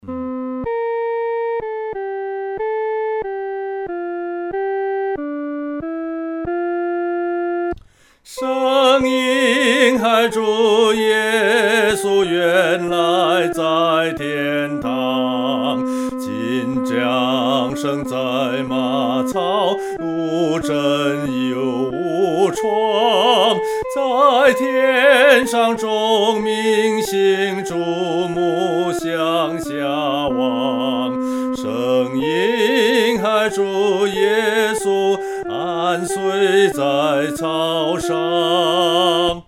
独唱（第一声）
在马槽里-独唱（第一声）.mp3